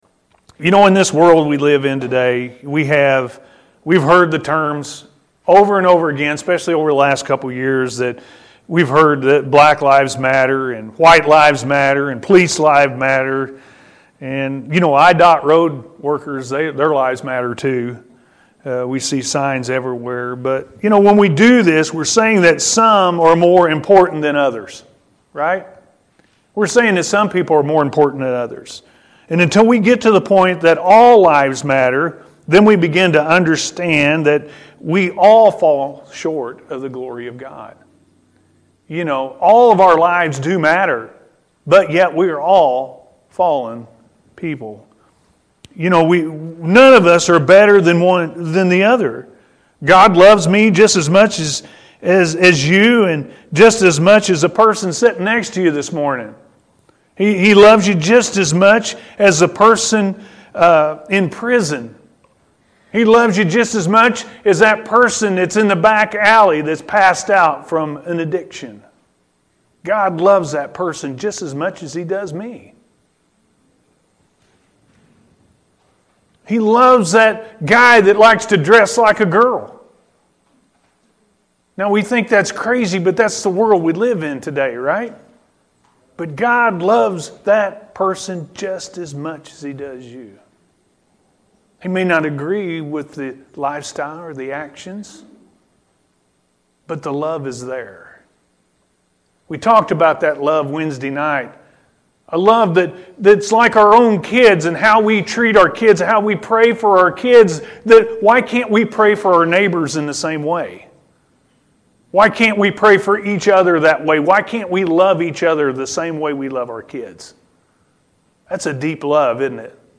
All Lives Matter To God-A.M. Service – Anna First Church of the Nazarene